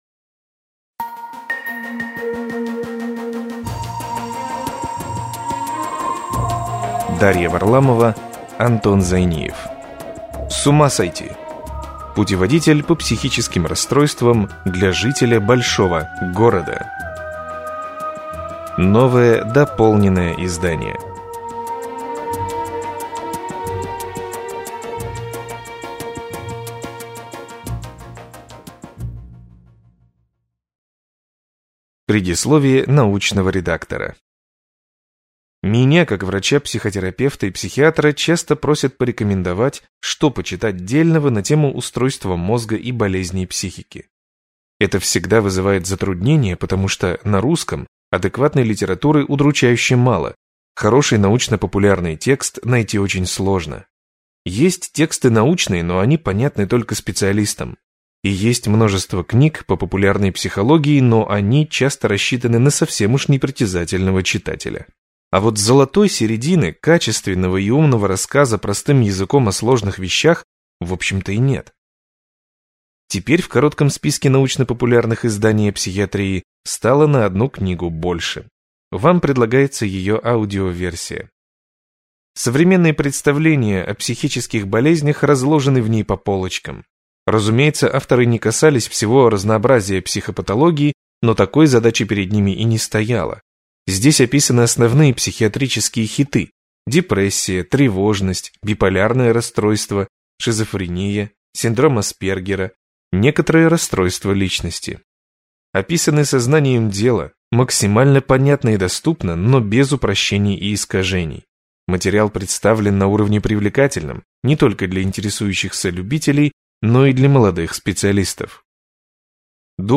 Аудиокнига С ума сойти!